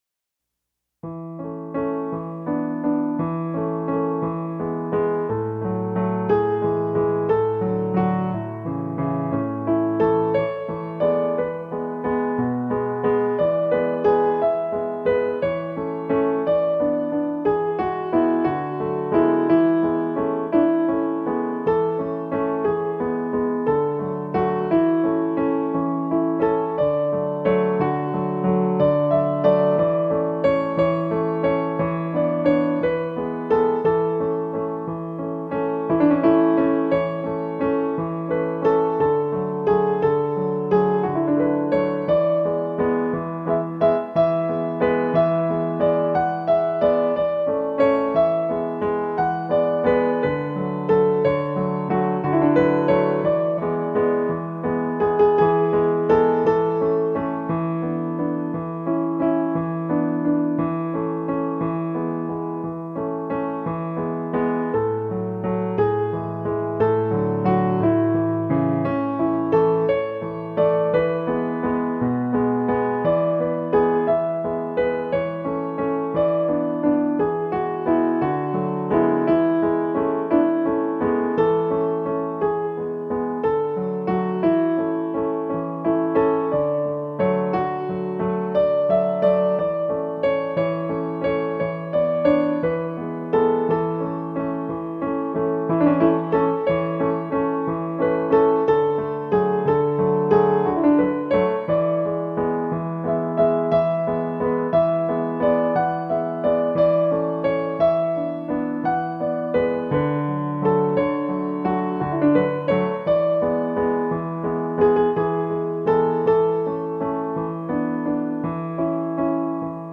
Teetering on the Brink -- Piano instrumental
Street_of_Secrets_Waltz.mp3